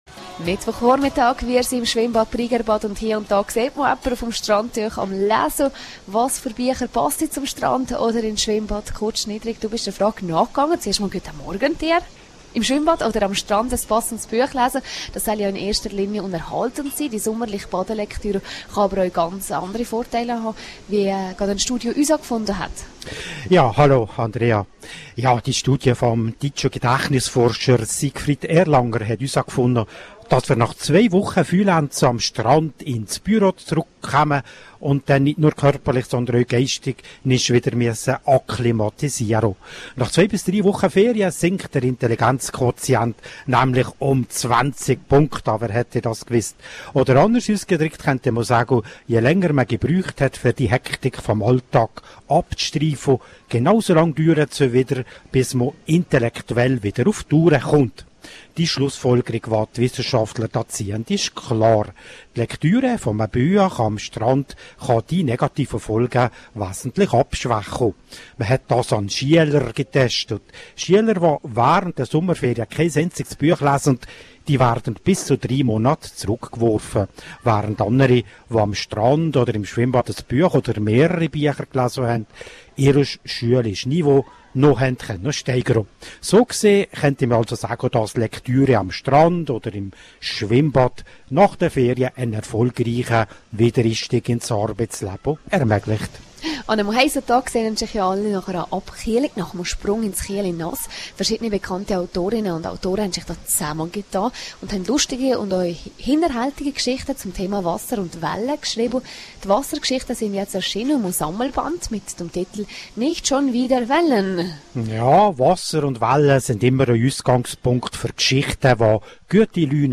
Live aus dem Schwimmbad Brigerbad